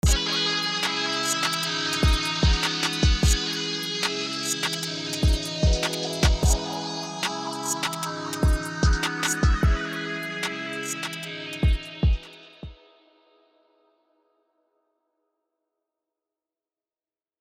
ישבתי על סרום וניסתי ליצור סאונד של גיטרה חשמלית זה נראה לי יצא יפה אבל מלוכלך (וזה יצא יותר מונו מאשר סטריאו נראה לי) אשמח לתגובה על זה מהמומחים האם זה באמת נשמע גיטרה חשמלית?
ונראה לי שאפשר לעשות על זה אתגר שתקחו את הקטע הזה ותתנו לו את הליווי באיזה זאנר שאתם רוצים (טמפו 150 אבל אפשר לשנות)